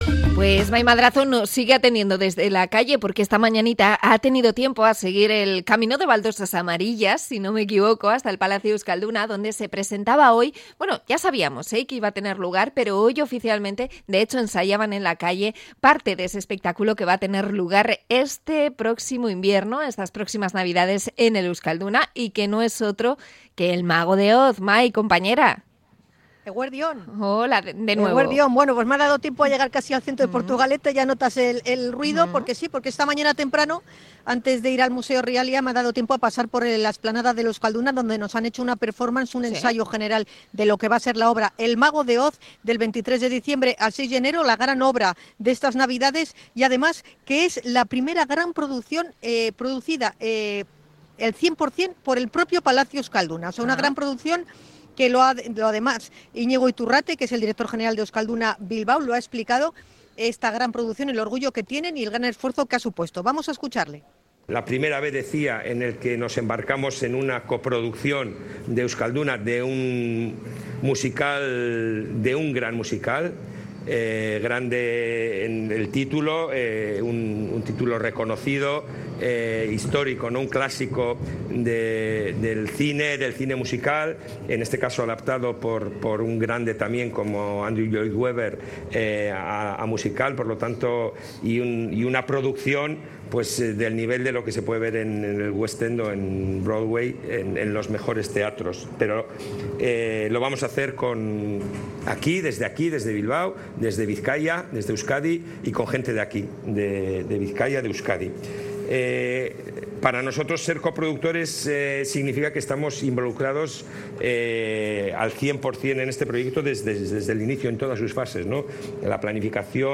Conexión desde la presentación de 'El Mago de Oz' desde el Palacio Euskalduna